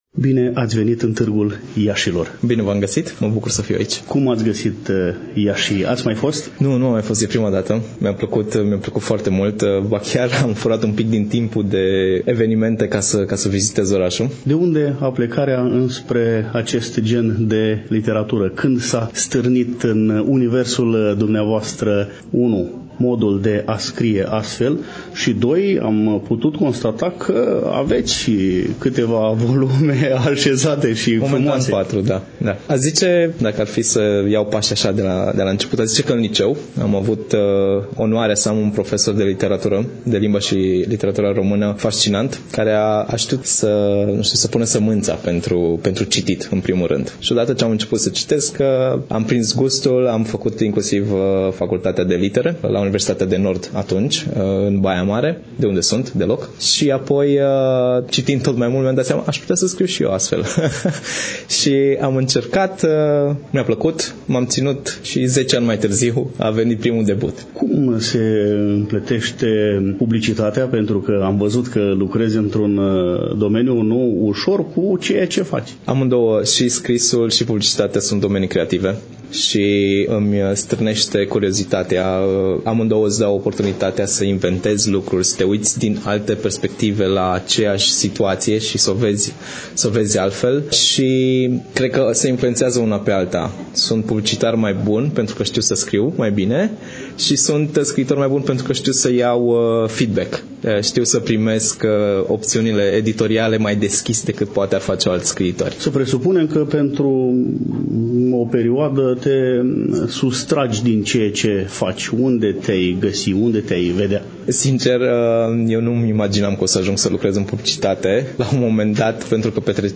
Știut este faptul că una dintre secțiunile FILIT este dedicată Casei Fantasy și, an de an, se desfășoară în parteneriat cu Biblioteca Județeană „Gheorghe Asachi” Iași, mai exact în incinta Filialei „Ion Creangă”, Casa Sindicatelor.
Concret, de la ediția a XI-a a FILIT,